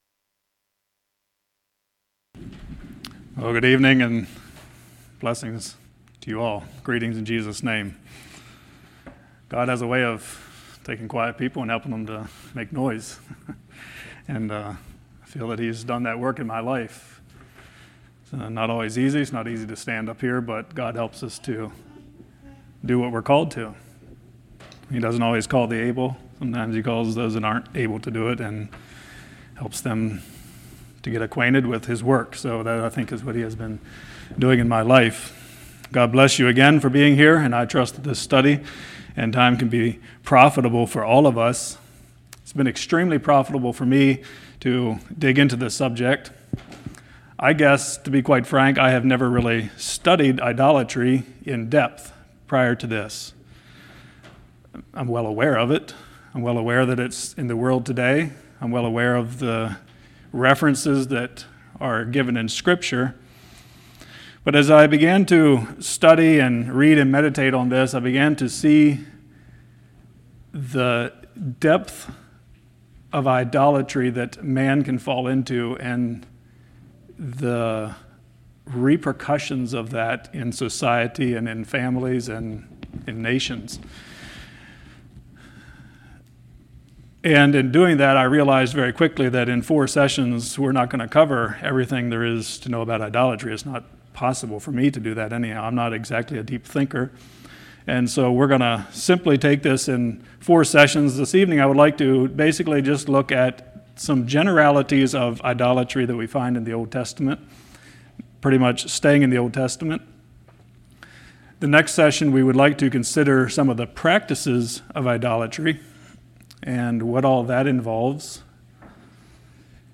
Isaiah 44:19 Service Type: Winter Bible Study Shall I fall down to the stock of a tree?